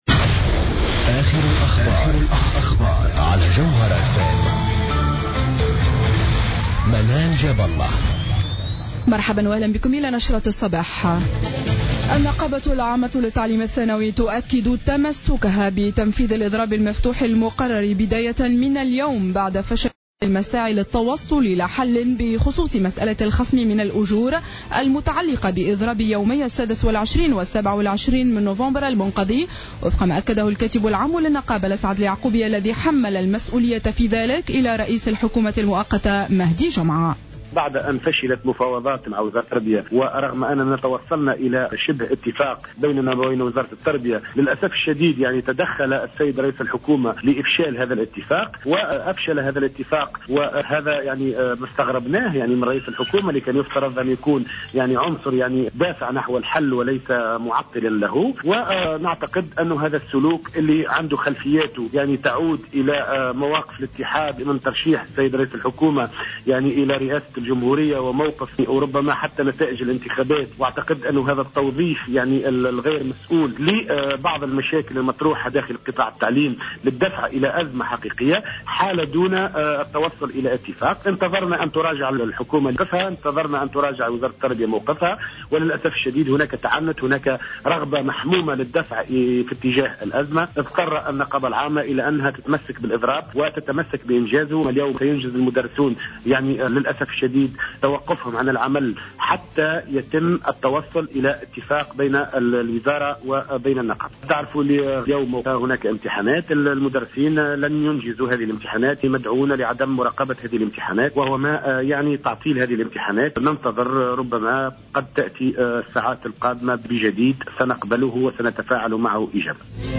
نشرة أخبار السابعة صباحا ليوم 08-12-14